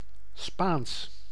Ääntäminen
IPA : /ˈspæn.ɪʃ/